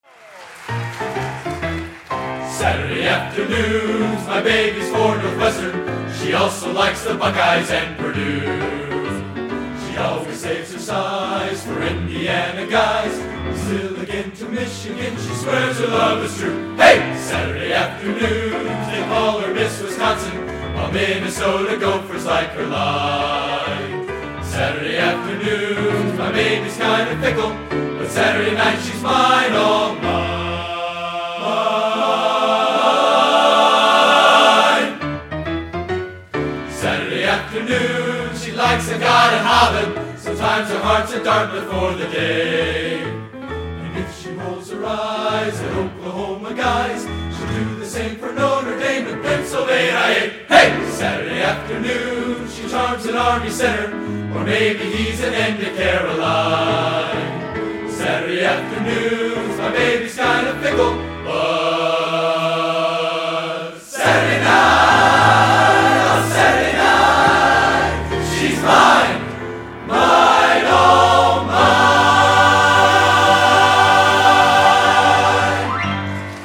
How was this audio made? Location: Northrop High School, Fort Wayne, Indiana